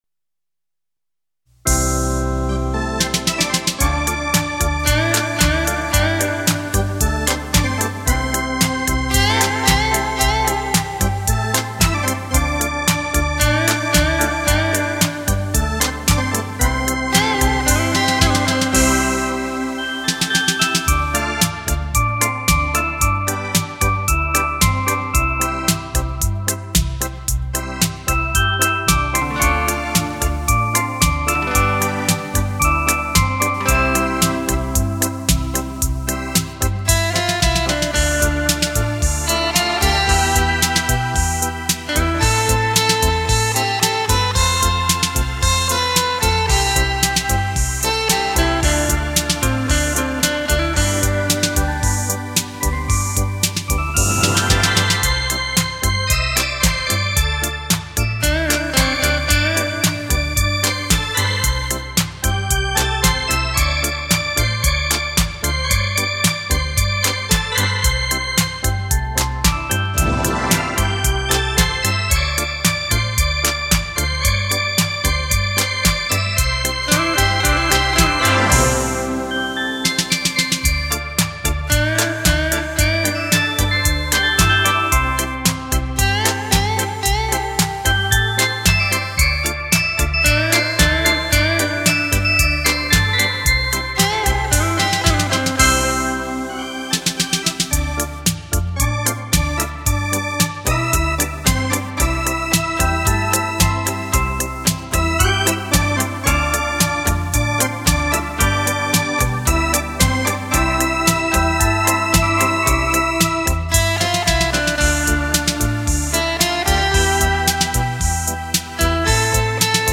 金指妙弹电子琴演奏--精心打造完美电音,电子技术融入到纯美的音乐当中,旋律美妙,独具韵味。
另外，电子琴还安装有混响回声，延长音、震音、和颤音等多项功能装置，表达各种情绪时运用自如。